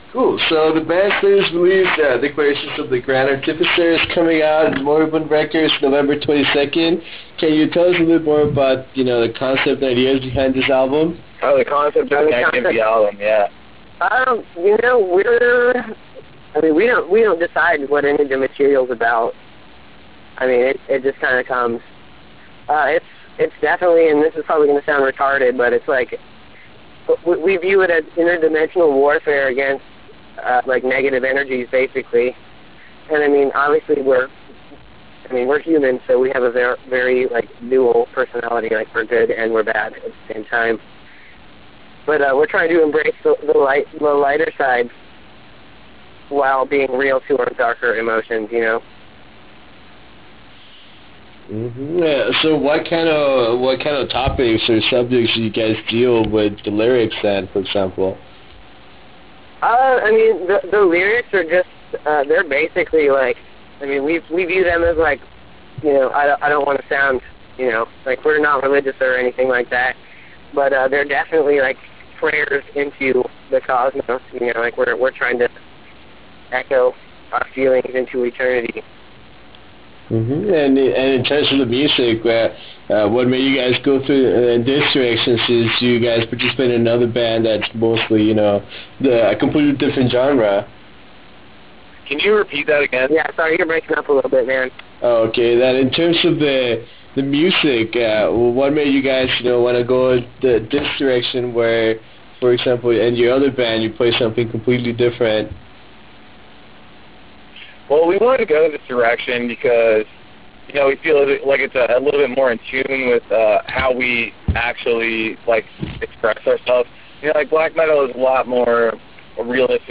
Interview with Chasma
Interview with Chasma.wav